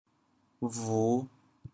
bhu5.mp3